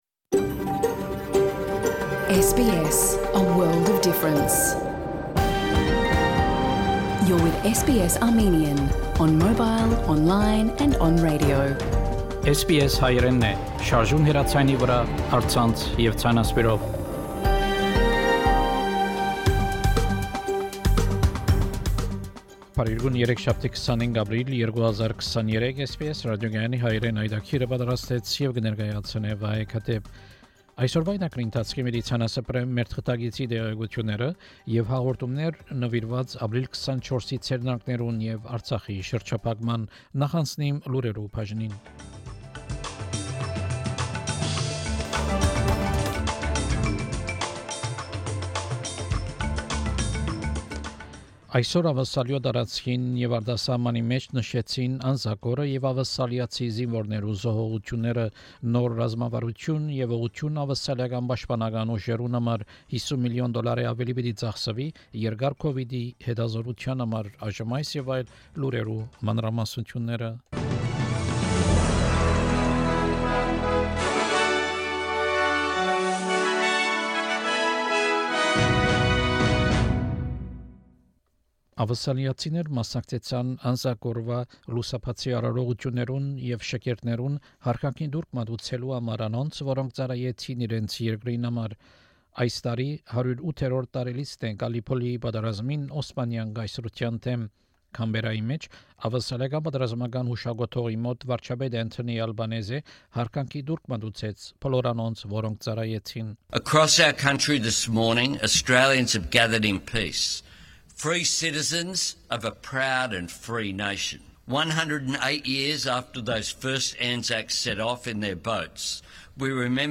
SBS Armenian news bulletin – 25 April 2023
SBS Armenian news bulletin from 25 April 2023 program.